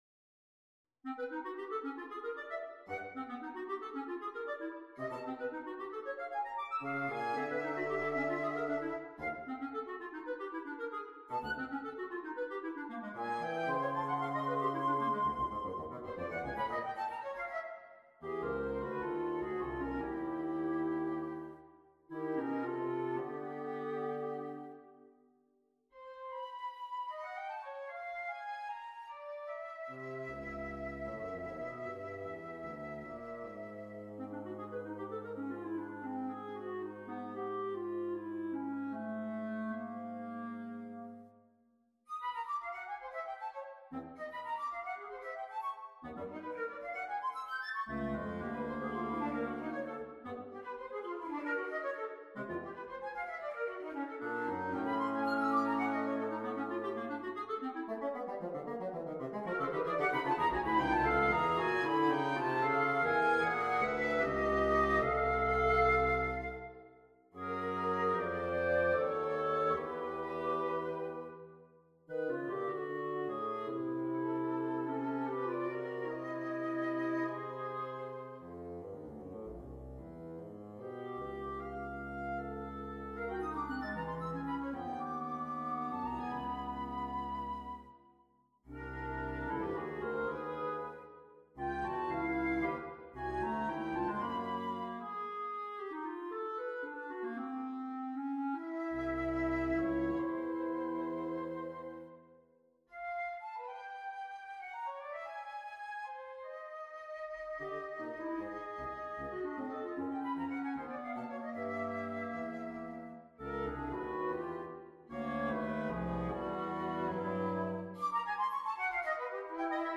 on a purpose-selected tone row
Andante frenetico
OK, even I am cocking my head to the side a bit at exactly how I think this mainly light-hearted and cheerful little piece belongs in a series purporting to represent some of the principal philosophical scriptures of the Dharmic tradition.
Besides which, woodwinds always eventually demand cheerful resolutions.